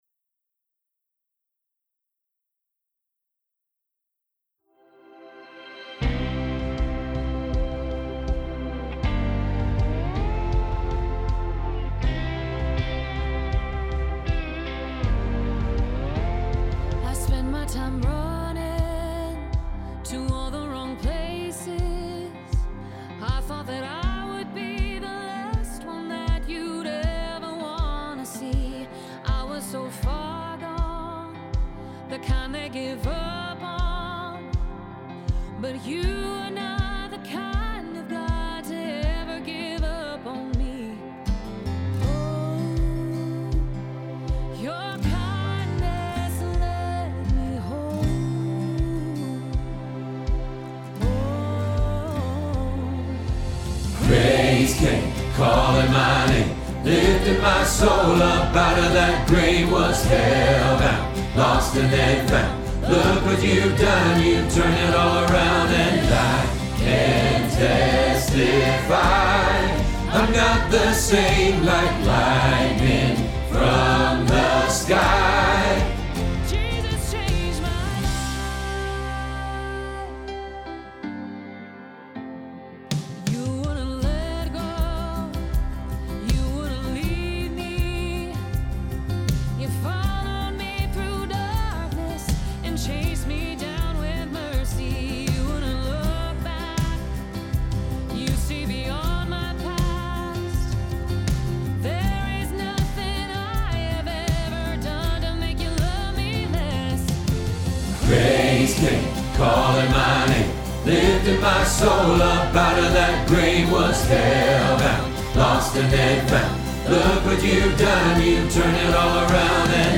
Jesus Changed My Life – Bass – Hilltop Choir
Jesus Changed My Life – Bass Hilltop Choir